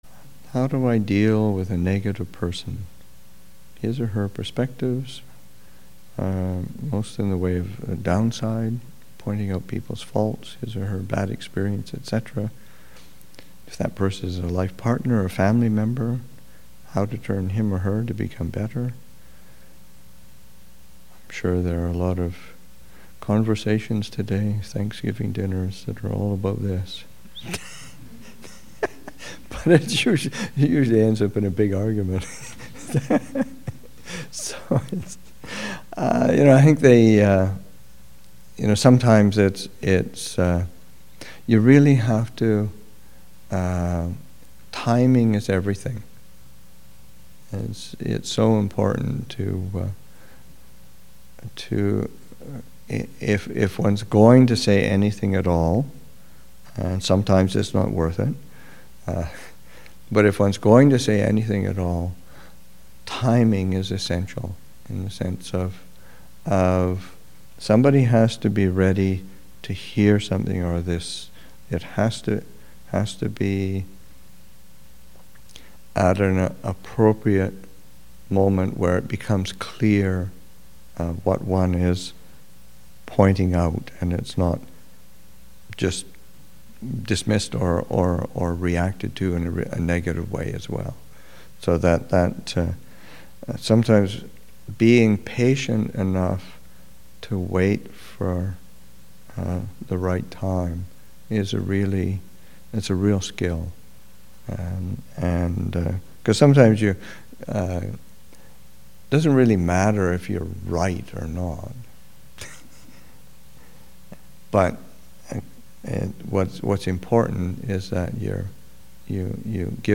Thanksgiving Retreat 2016, Session 6 – Nov. 24, 2016